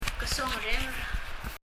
発音